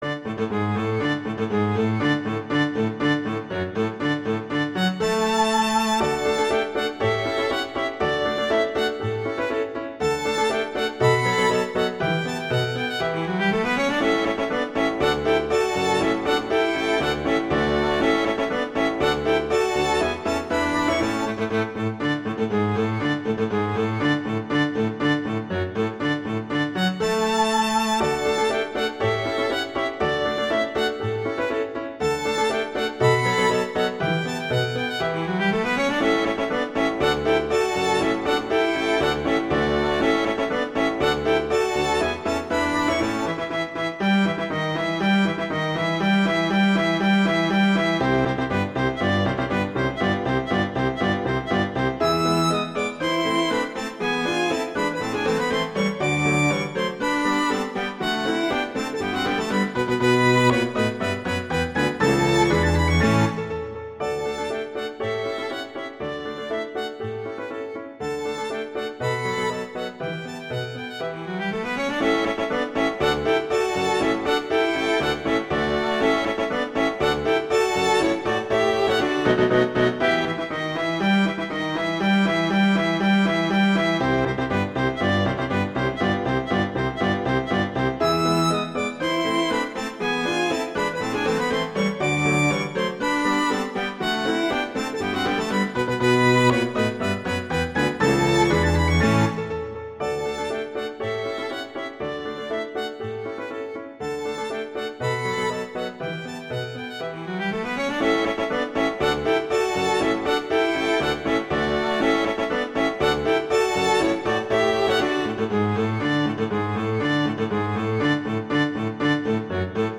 military March
classical
D major
♩=120 BPM